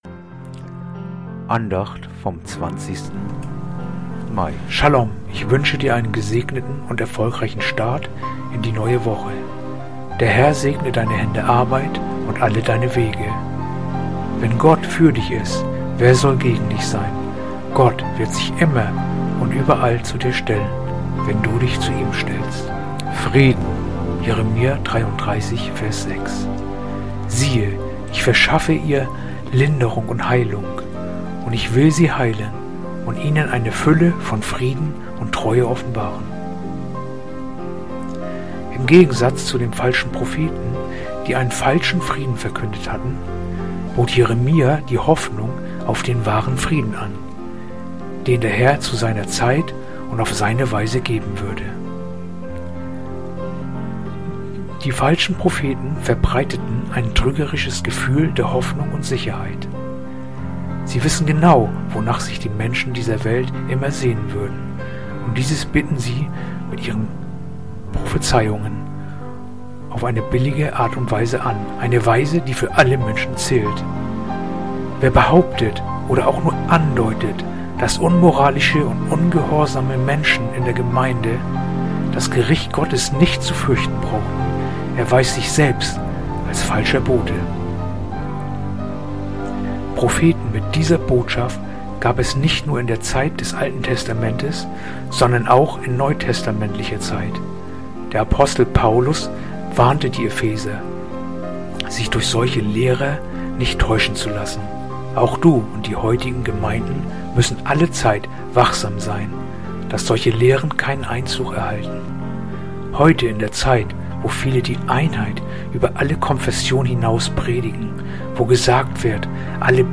heutige akustische Andacht
Andacht-vom-12-Juli-Jeremia-336.mp3